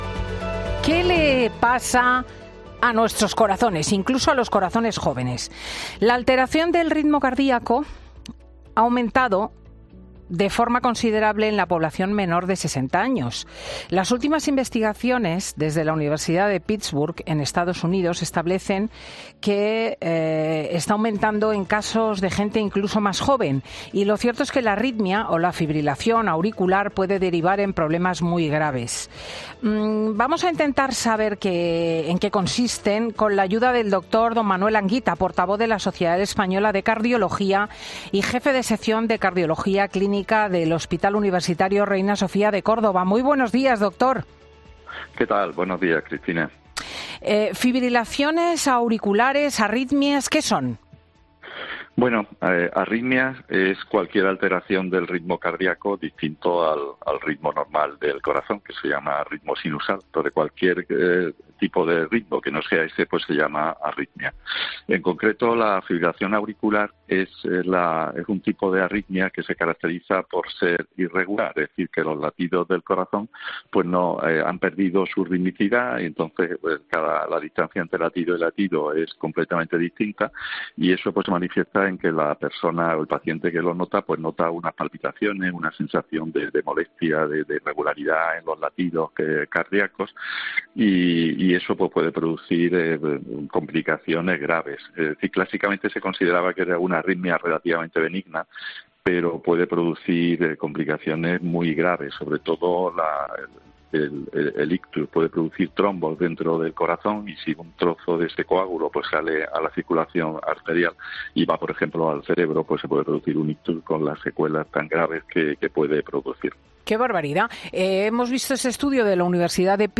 pasa por los micrófonos de 'Fin de Semana' para analizar el incremento de las arritmias en jóvenes